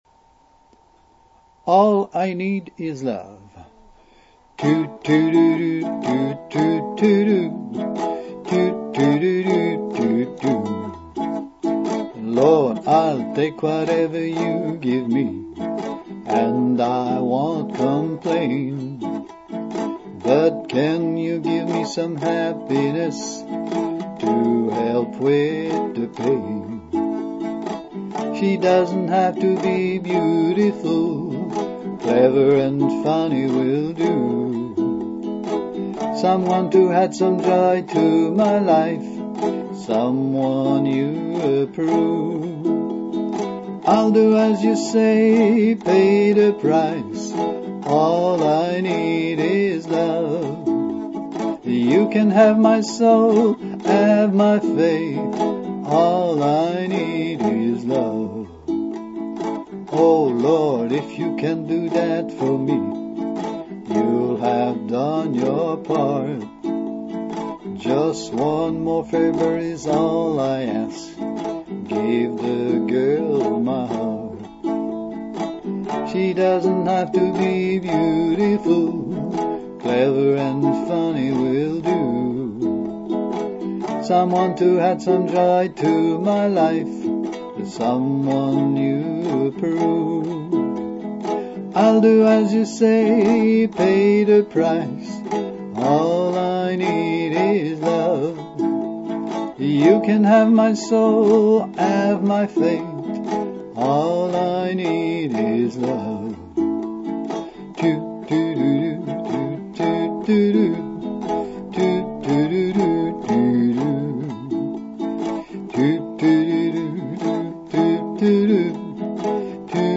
allineedisloveuke.mp3